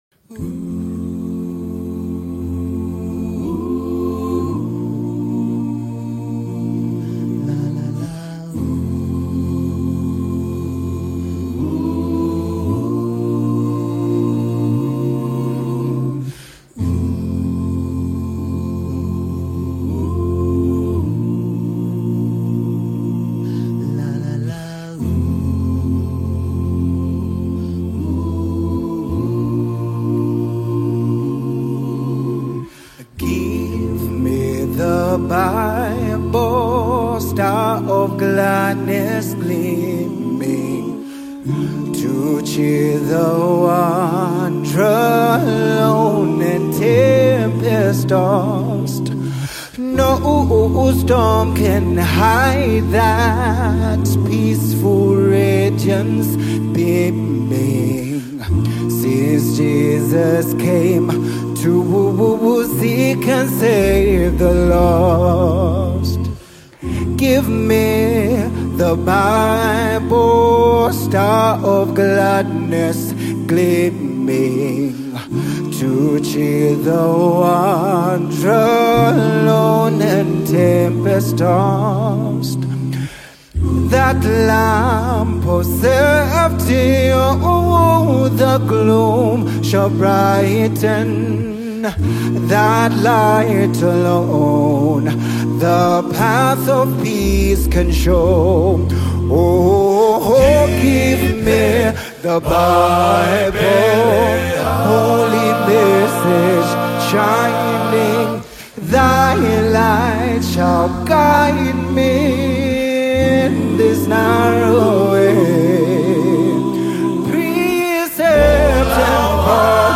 SCRIPTURE-CENTERED WORSHIP ANTHEM | 2025 ZAMBIA GOSPEL
PASSIONATE VOCALS